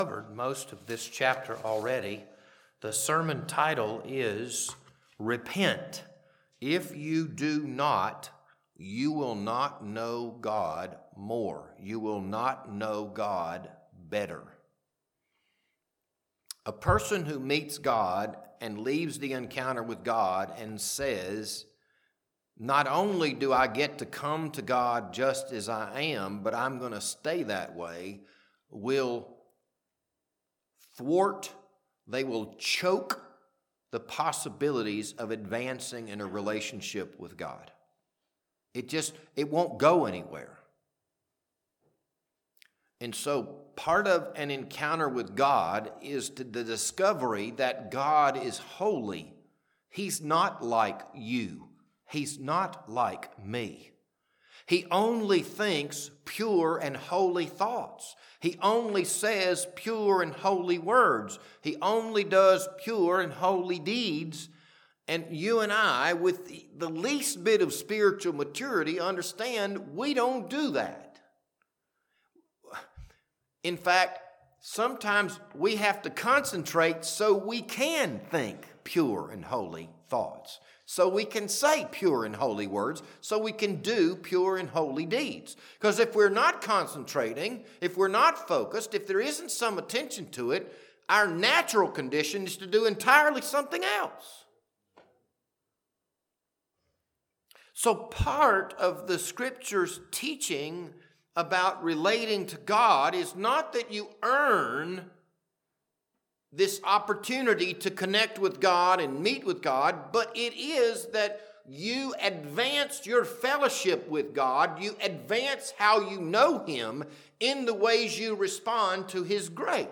This Sunday evening sermon was recorded on March 22nd, 2026.